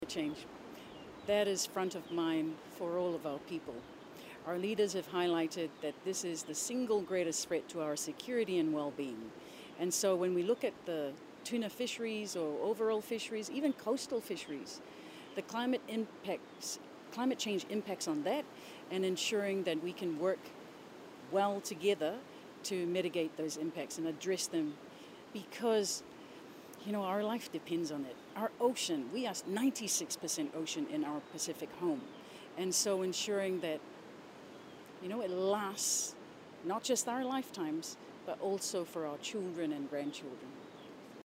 This was revealed by the Director General of the Pacific Islands Forum Fisheries Agency-Dr. Manu Tupou-Roosen during the week-long National Fisheries Summit and Strategic Development Opportunity Consultation currently underway on the island